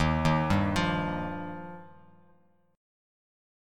D#add9 chord